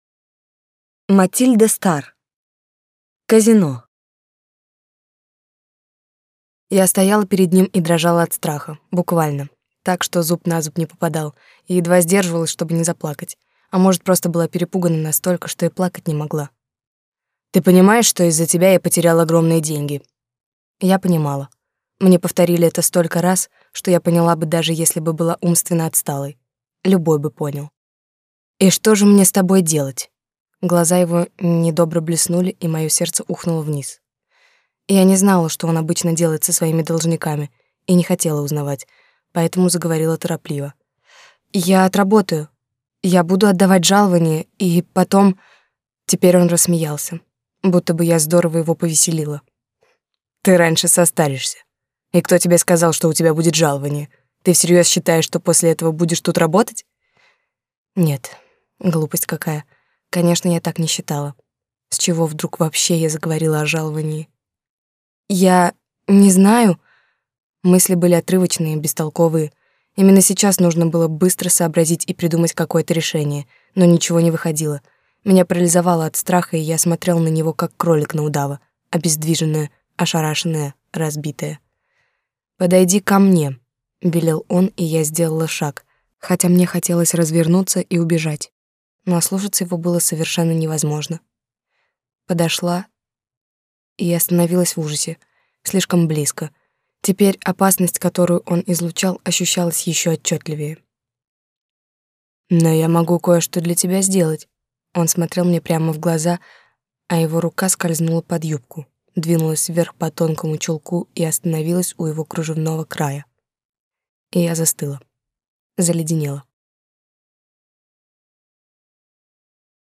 Aудиокнига Казино